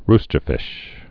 (rstər-fĭsh)